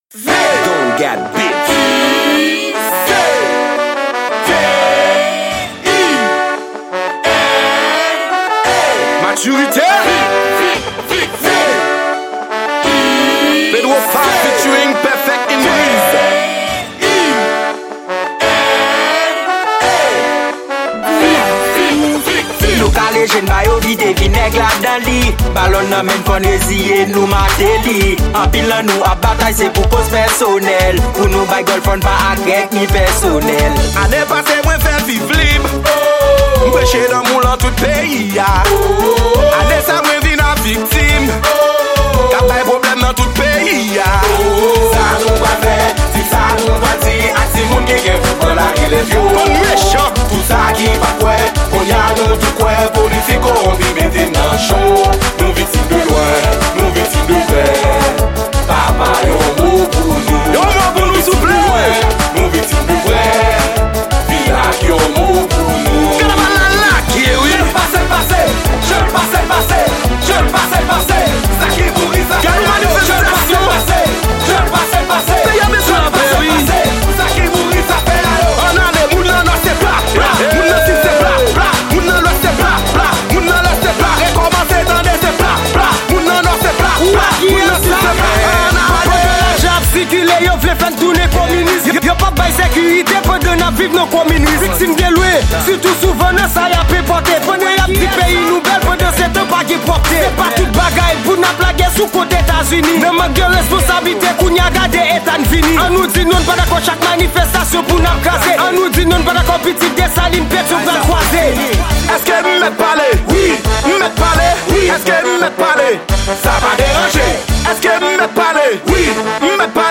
Genre: K-naval.